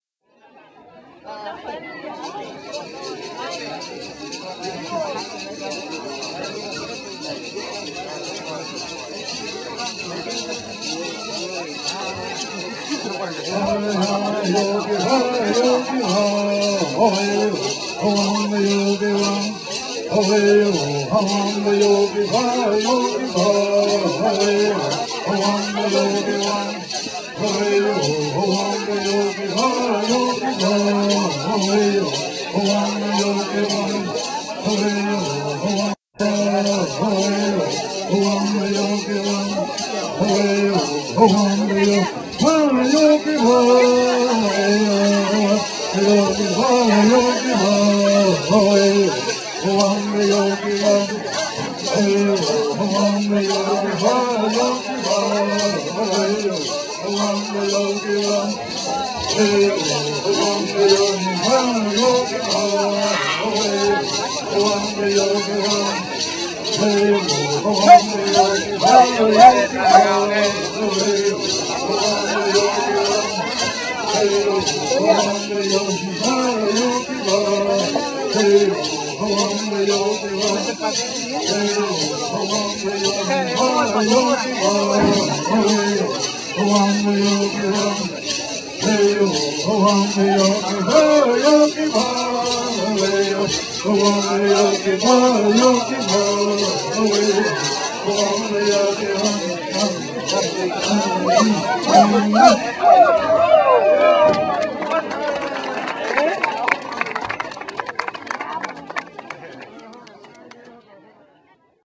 The Kumeyaay annual Native American fiesta in San Jose de la Zorra drew a couple hundred guests and Indian singers from northwest tribal ejidos of Baja, California, including southwestern Arizona and Southern California singers dancers performers.